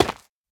Minecraft Version Minecraft Version snapshot Latest Release | Latest Snapshot snapshot / assets / minecraft / sounds / block / basalt / break4.ogg Compare With Compare With Latest Release | Latest Snapshot
break4.ogg